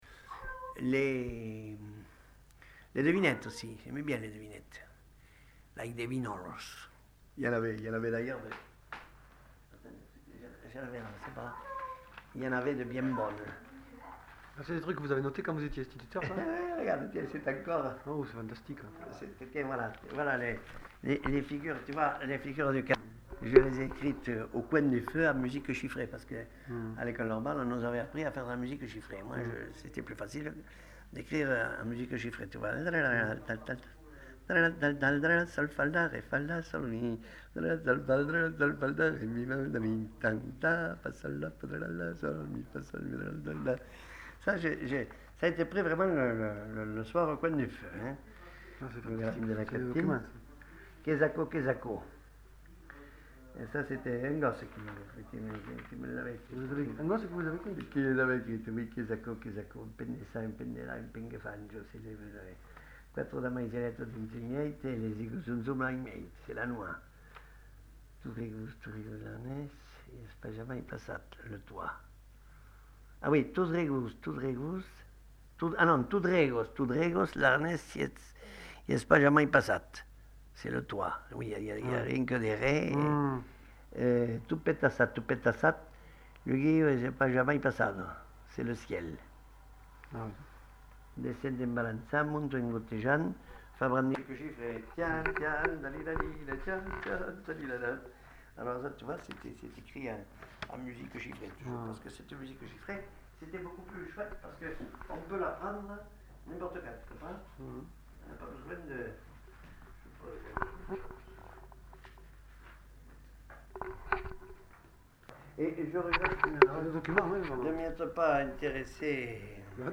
Aire culturelle : Lauragais
Effectif : 1
Type de voix : voix d'homme
Production du son : récité
Classification : devinette-énigme